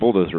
bulldozer.mp3